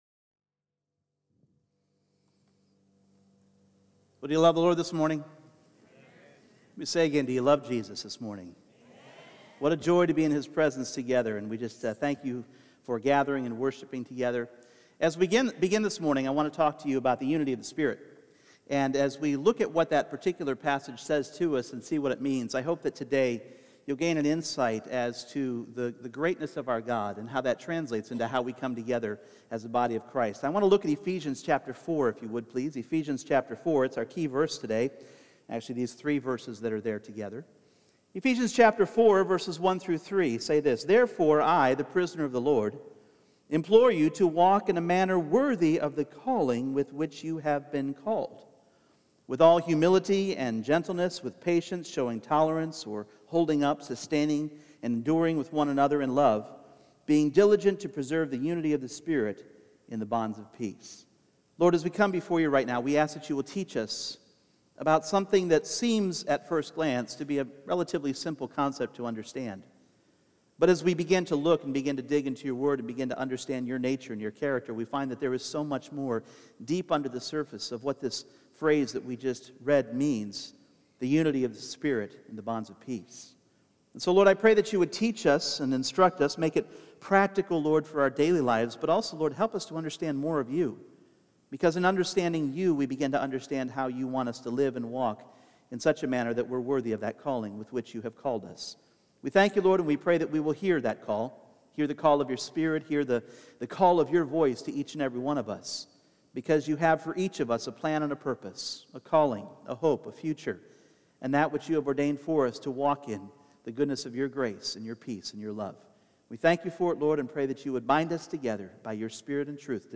Individual Messages Service Type: Sunday Morning There is a unity within the Godhead